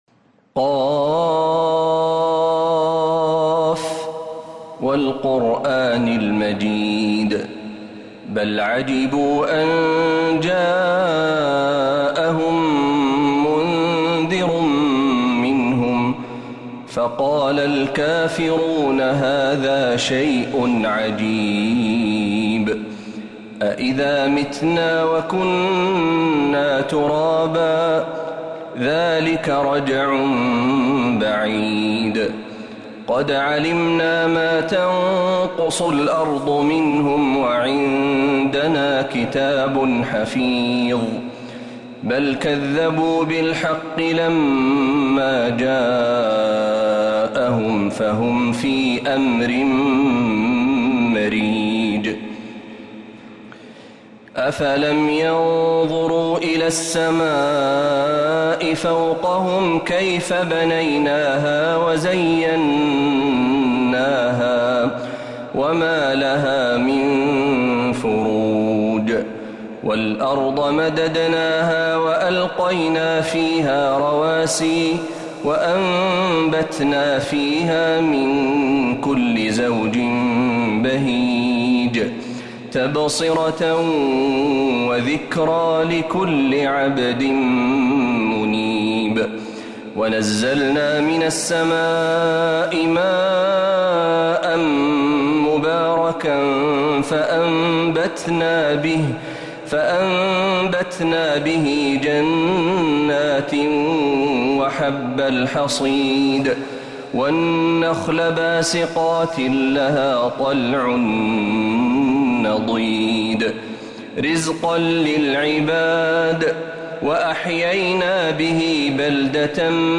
سورة ق كاملة من الحرم النبوي